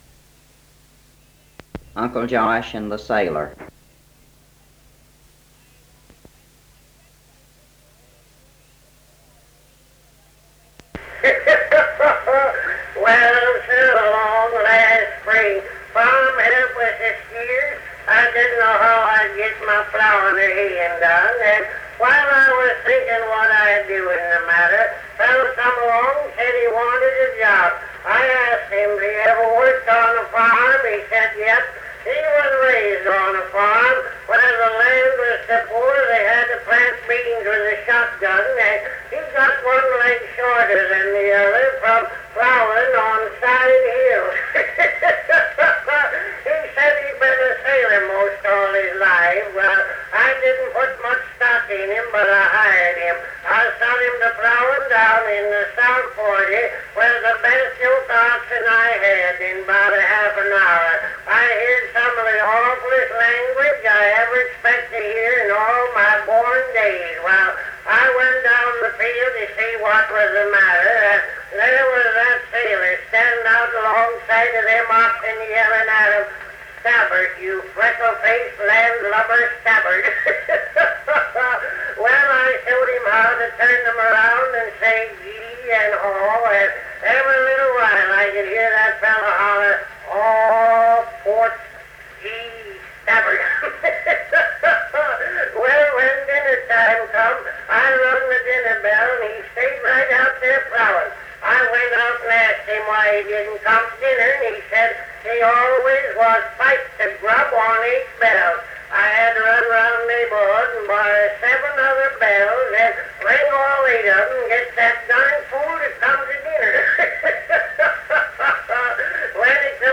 Cal Stewart's comedy routine, Uncle Josh and the sailor.